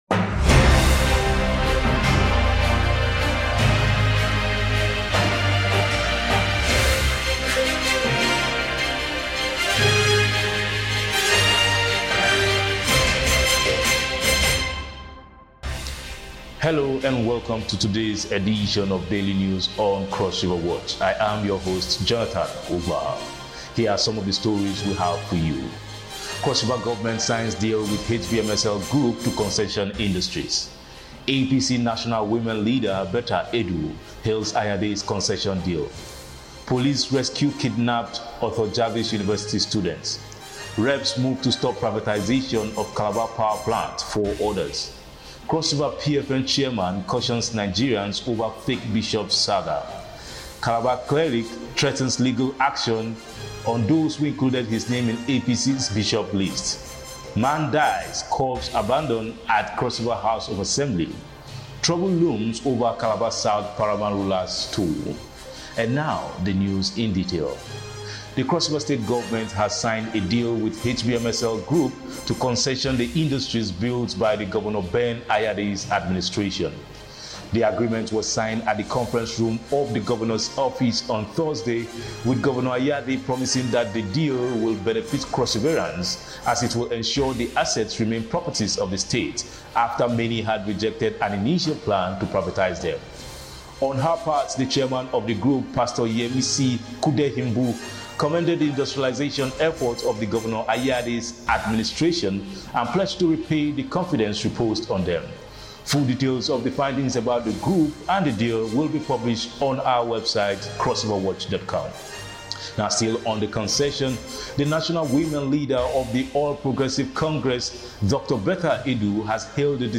Breaking News CrossRiverWatch TV News Podcast